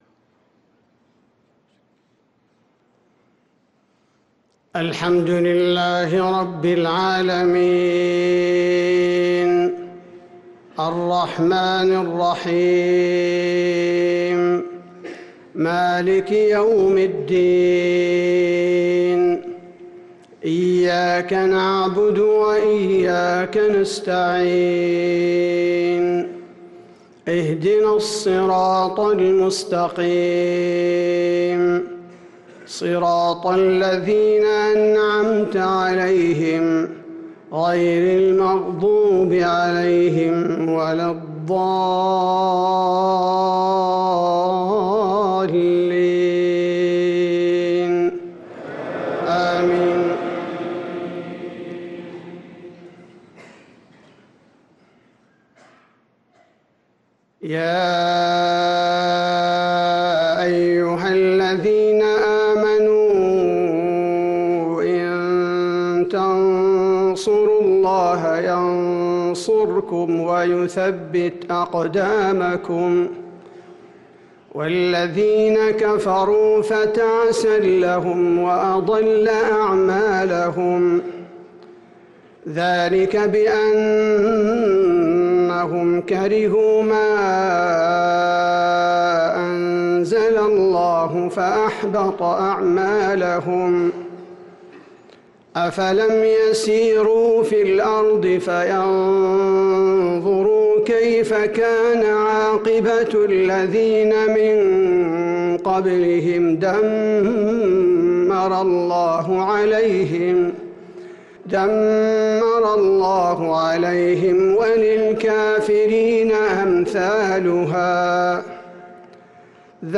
صلاة المغرب للقارئ عبدالباري الثبيتي 17 رجب 1445 هـ